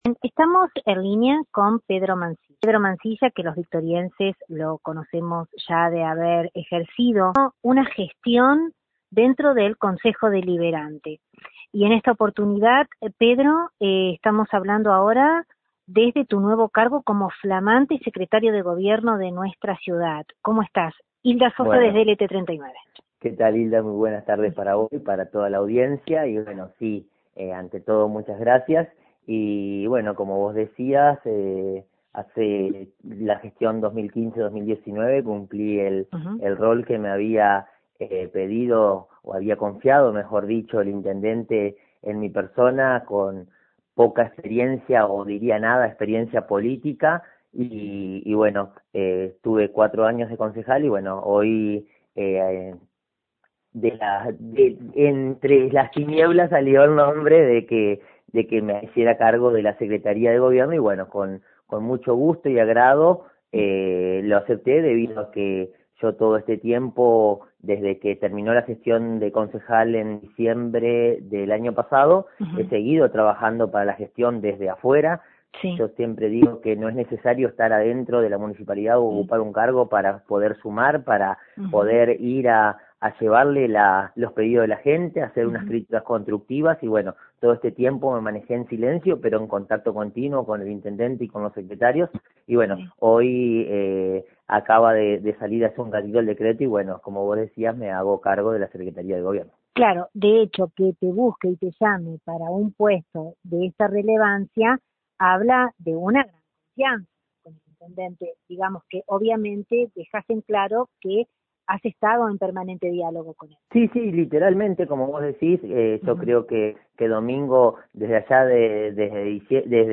Dialogamos con el flamante Secretario de Gobierno municipal, el ex edil Pedro Mansilla